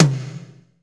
Index of /90_sSampleCDs/Northstar - Drumscapes Roland/TOM_Toms 1/TOM_S_S Toms x
TOM S S H0HR.wav